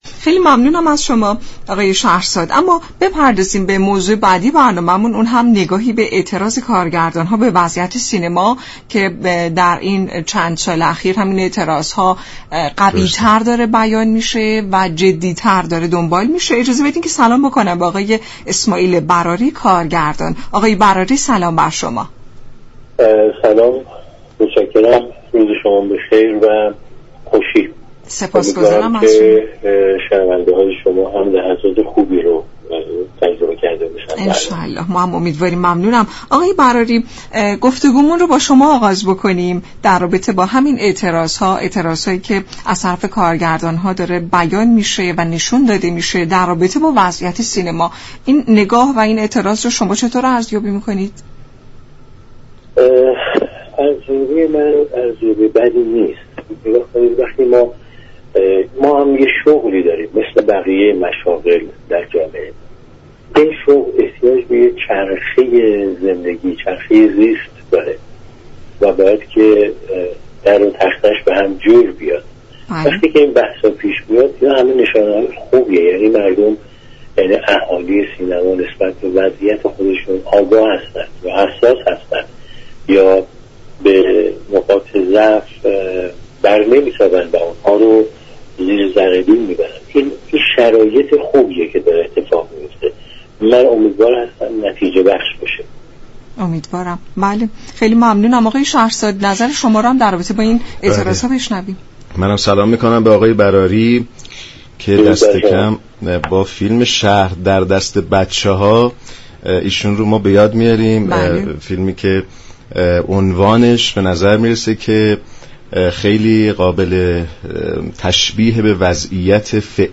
یك كارگردان سینما در گفت و گو با برنامه حوض نقره گفت: اسامی فیلم های اكران شده در سال های اخیر نشان می دهد سینمای ایران به لحاظ محتوا دچار ریختگی و از هم پاشیدگی شده است.